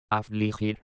Hanno il suono di una "h" aspirata la consonante -g-, usata nei gruppi -ge, gi-, e la lettera -j- sempre.